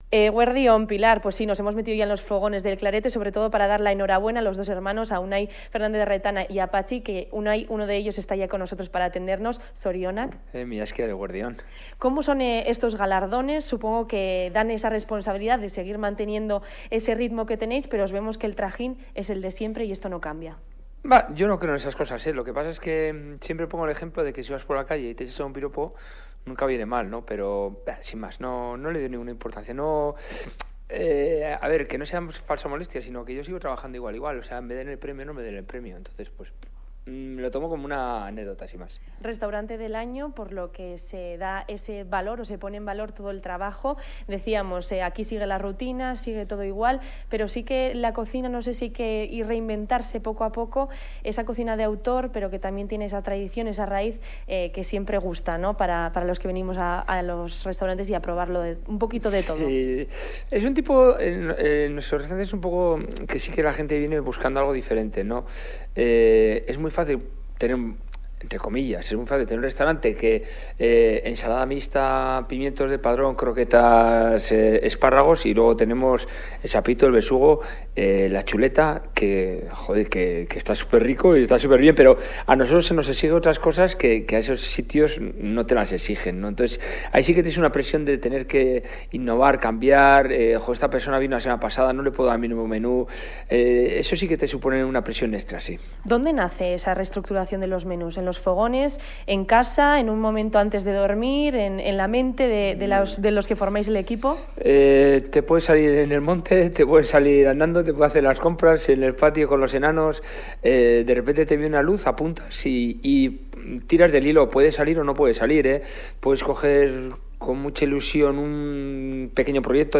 El Clarete, restaurante del año en los galardones Vitoria Gourmets. Nos adentramos con la unidad móvil en los fogones de El Clarete, restaurante de la calle Cercas Bajas, para conocer su día a día.